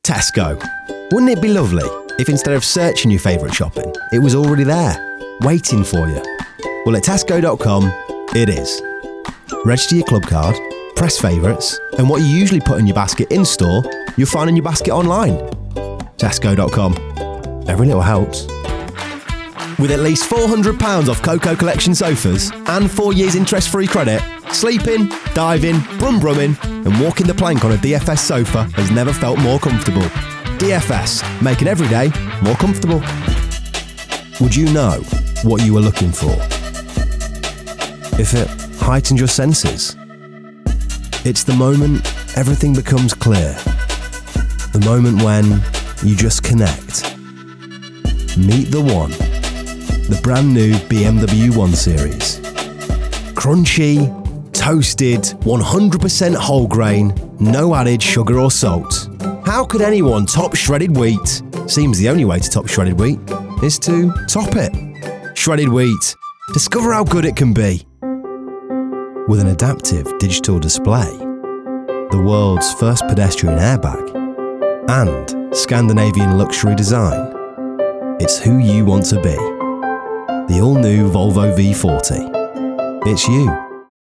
Female 30s , 40s Australian English (Native) , British English Approachable , Authoritative , Confident , Corporate , Deep , Engaging , Friendly , Gravitas , Smooth , Warm